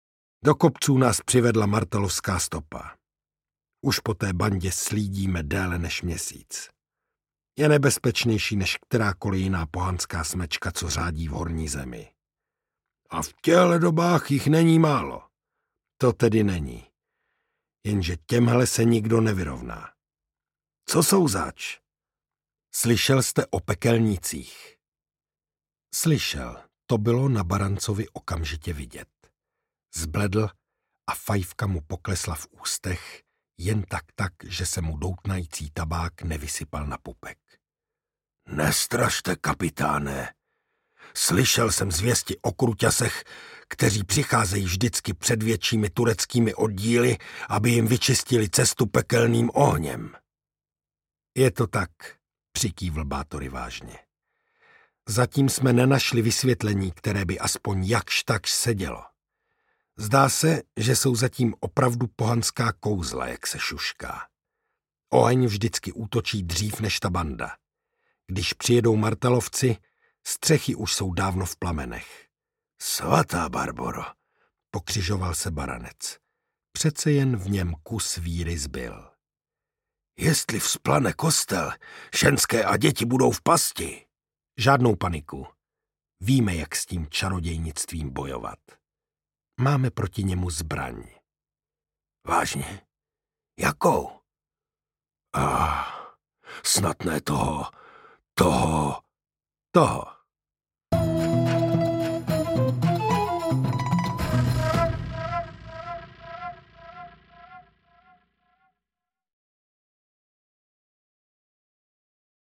Pekelníci audiokniha
Ukázka z knihy
pekelnici-audiokniha